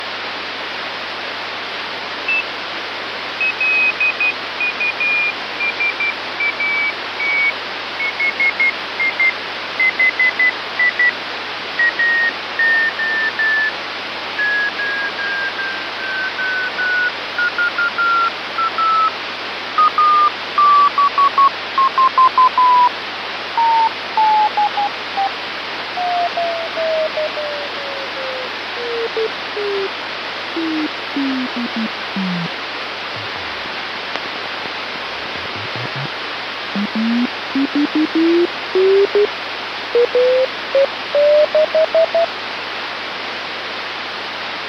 the CW signal on 437.125 MHz